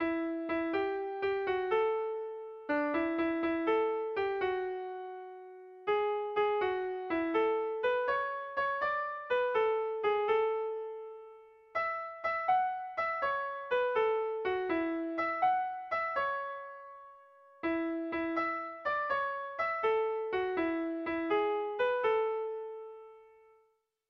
Sentimenduzkoa
Zortziko txikia (hg) / Lau puntuko txikia (ip)
ABDE